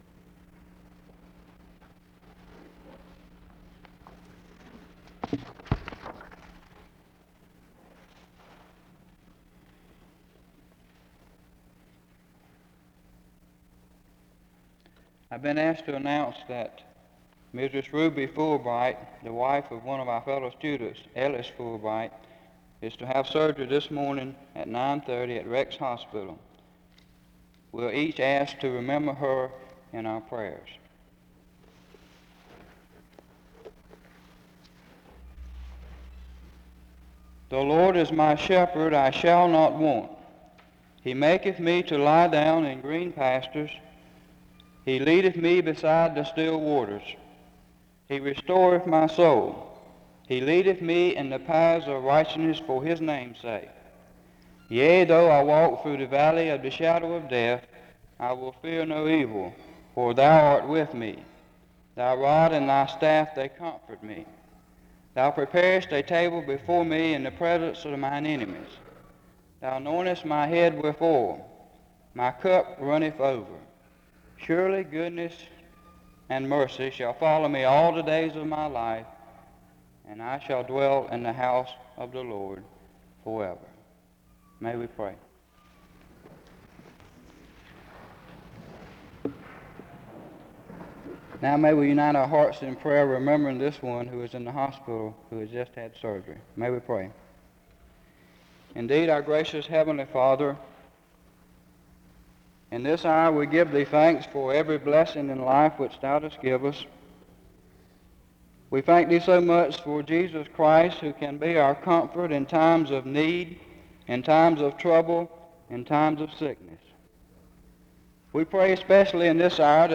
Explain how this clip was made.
The service began with some opening scripture reading of Psalm 23 and John 4:31-36.